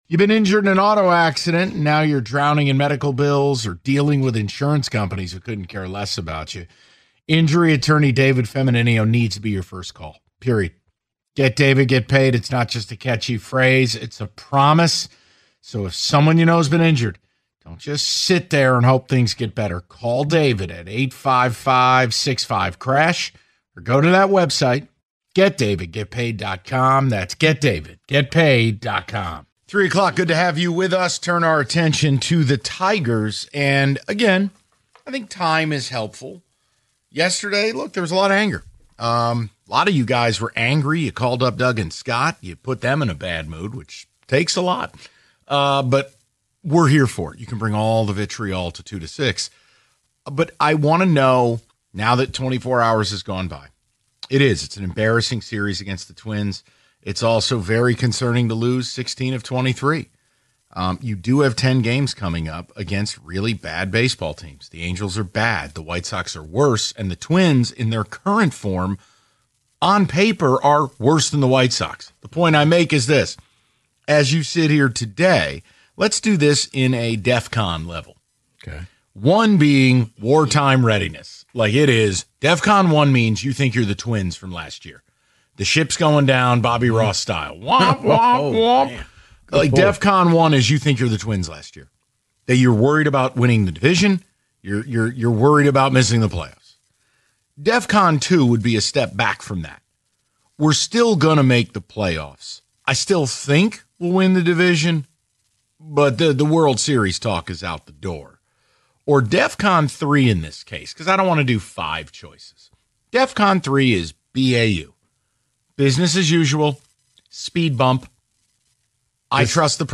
They took plenty of your calls and read your ticket texts throughout the hour.